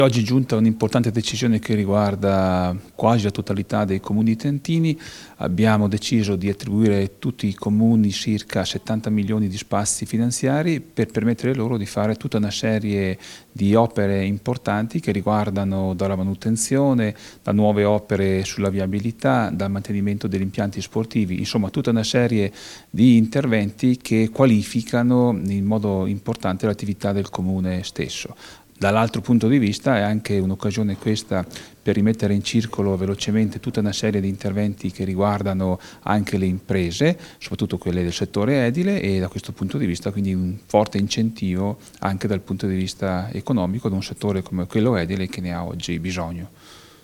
In allegato intervista all'assessore Carlo Daldoss Fonte: Ufficio Stampa Pubblica Amministrazione Versione Stampabile Immagini Visualizza Audio DALDOSS (6) Scarica il file (File audio/mpeg 1,08 MB)